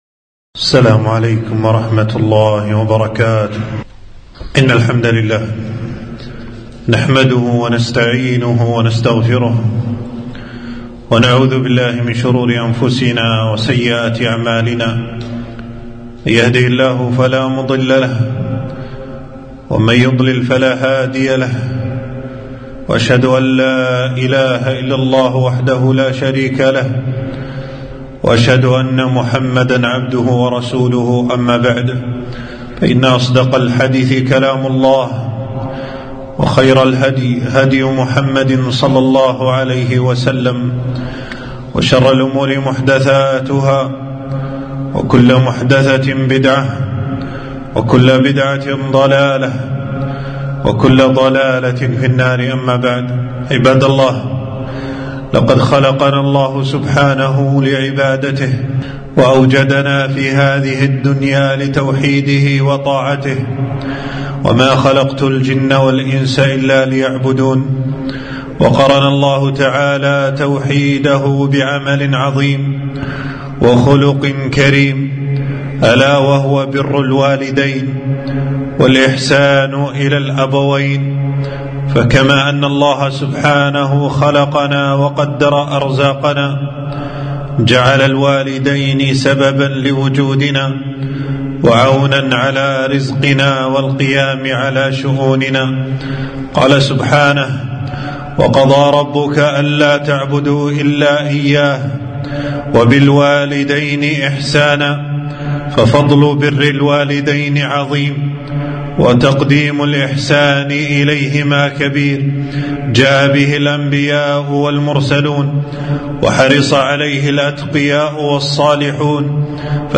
خطبة - بر الوالدين من أعظم القربات